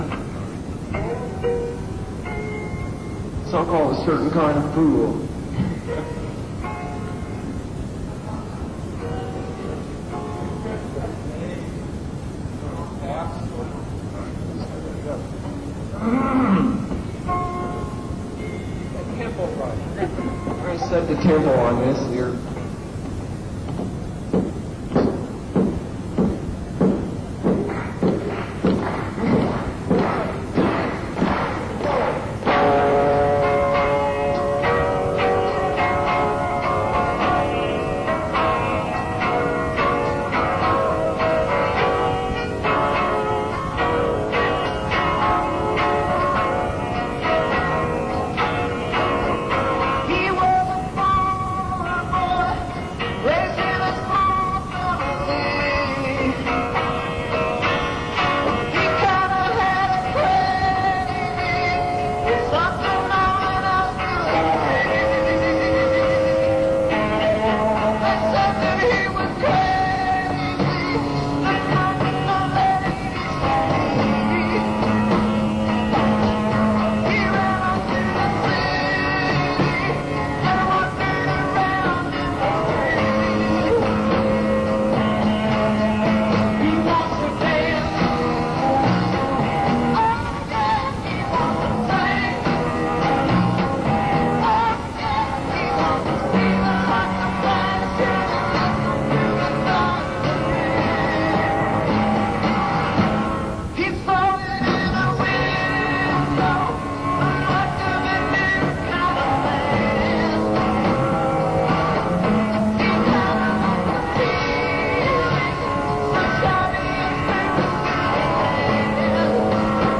Royal Festival Hall, London